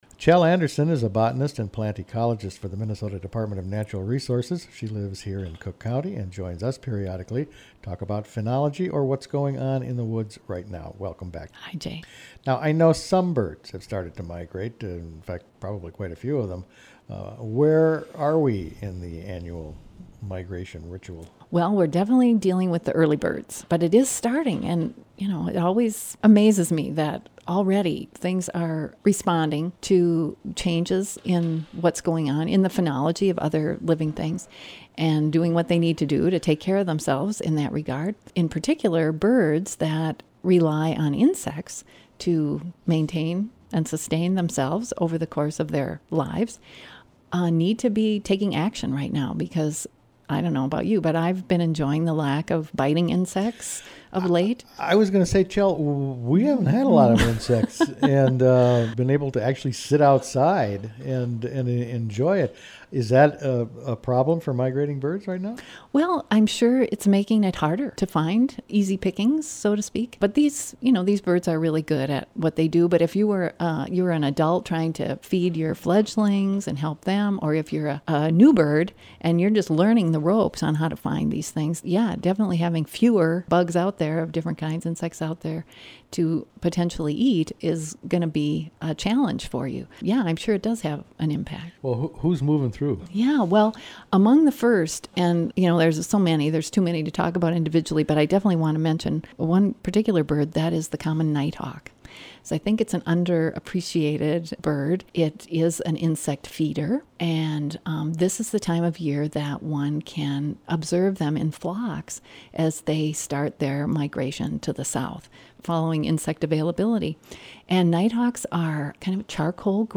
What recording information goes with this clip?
Late Summer Means The Bird Migration Is Near | WTIP North Shore Community Radio, Cook County, Minnesota